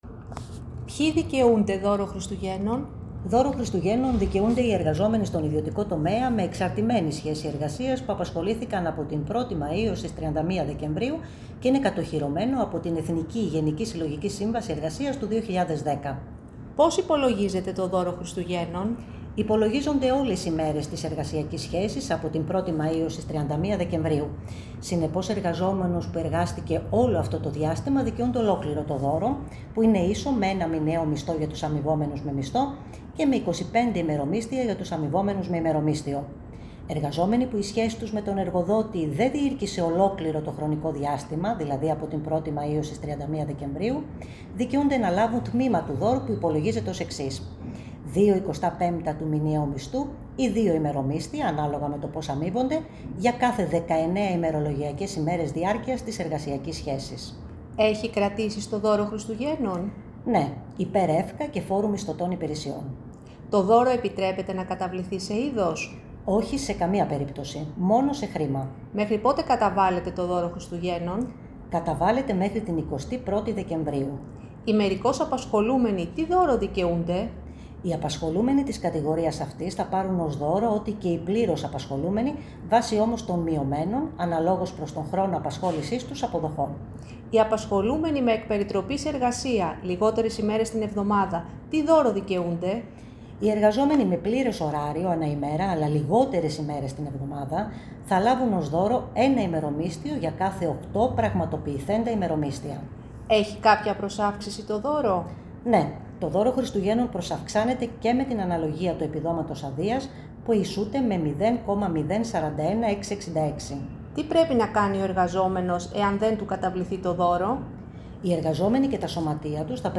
KEΠΕΑ-ΓΣΕΕ: Σύντομες ερωτοαπαντήσεις για το Δώρο Χριστουγέννων (ηχητικός οδηγός)
Έναν ηχητικό οδηγό με εννέα ερωτήσεις και απαντήσεις για το Δώρο Χριστουγέννων, έδωσε στη δημοσιότητα το Κέντρο Πληροφόρησης Εργαζομένων & Ανέργων της ΓΣΕΕ (ΚΕ.Π.Ε.Α./ΓΣΕΕ).